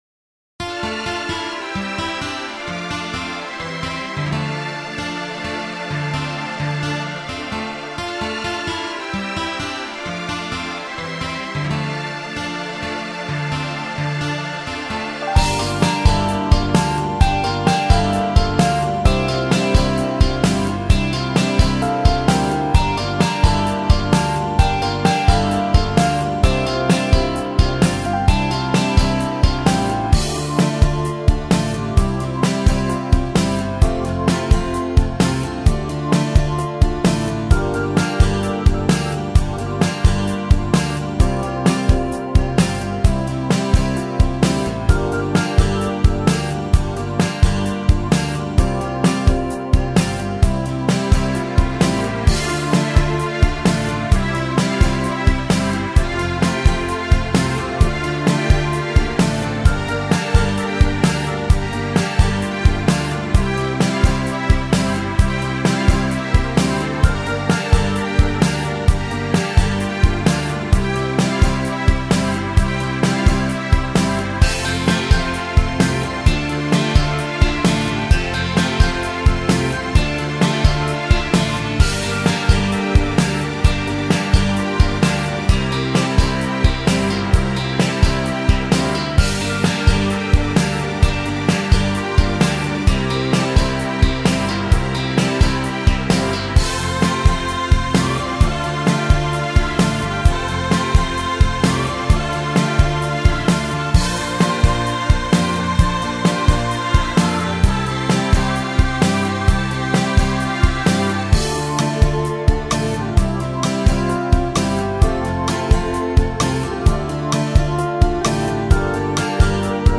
Минусовки (караоке)